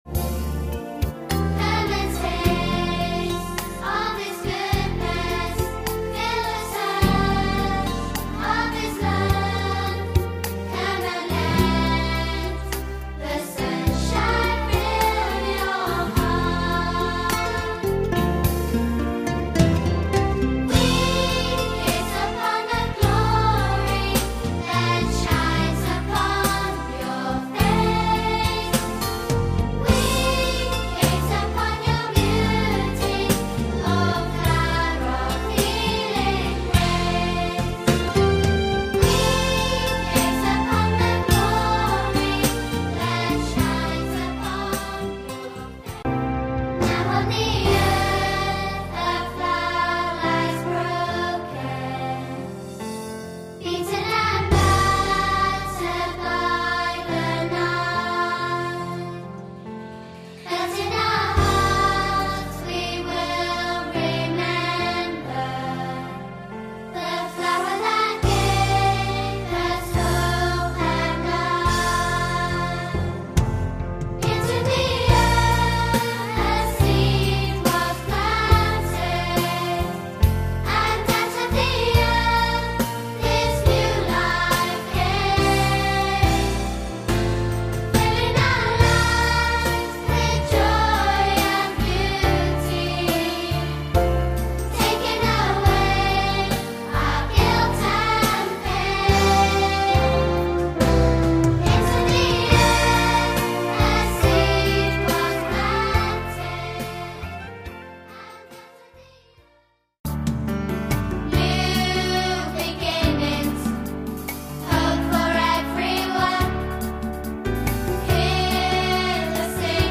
An Easter musical